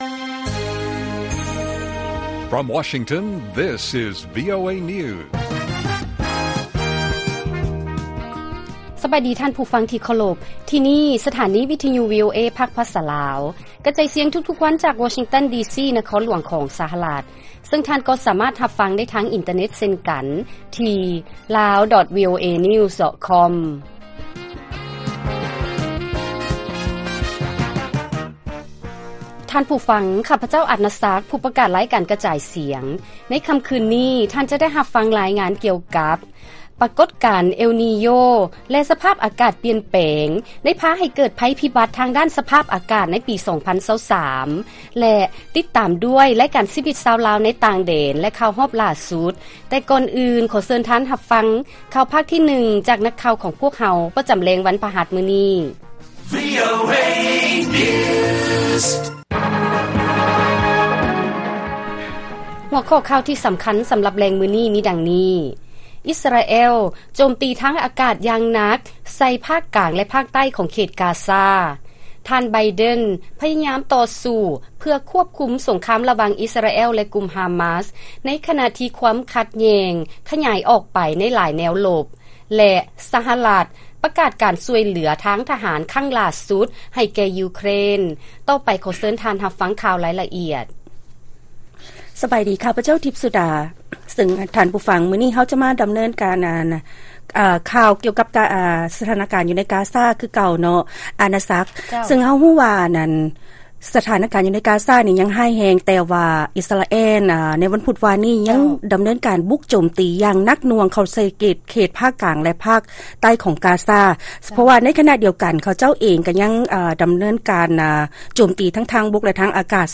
ລາຍການກະຈາຍສຽງຂອງວີໂອເອ ລາວ: ອິສຣາແອລ ໂຈມຕີທາງອາກາດ ຢ່າງໜັກໜ່ວງ ໃສ່ ພາກກາງ ແລະພາກໃຕ້ ຂອງແຫຼມກາຊາ